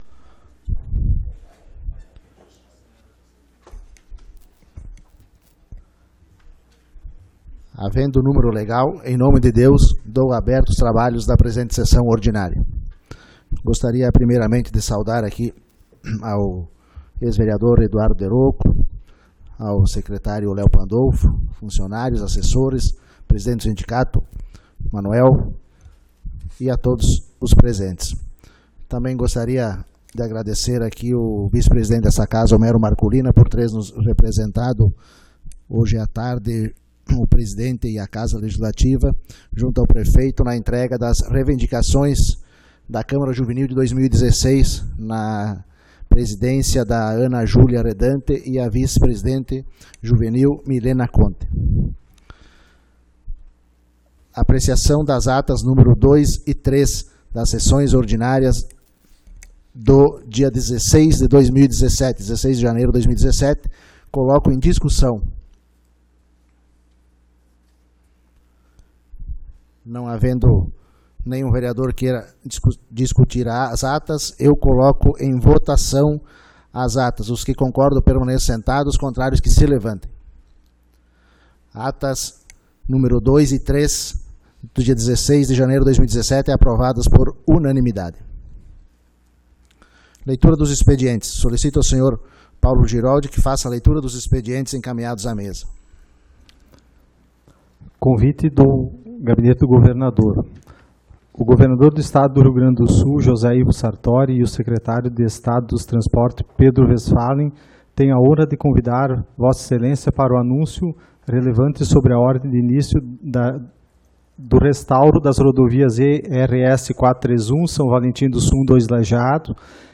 Sessão Ordinária do dia 23 de Janeiro de 2017